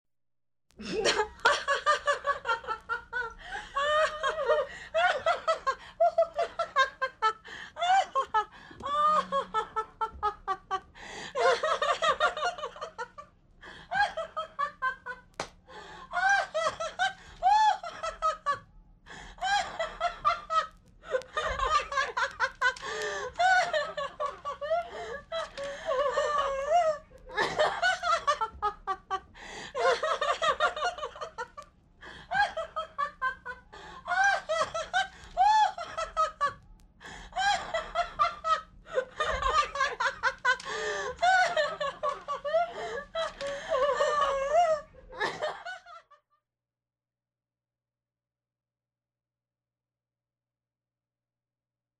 100 Sound Effects Vol.3 - 88 - Laughing - 2 Females - Madacy Music Group In
Tags: radio funny sounds